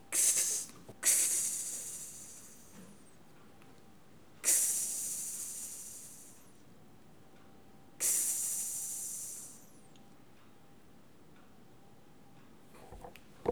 Les sons ont été découpés en morceaux exploitables. 2017-04-10 17:58:57 +02:00 2.3 MiB Raw History
serpent_01.wav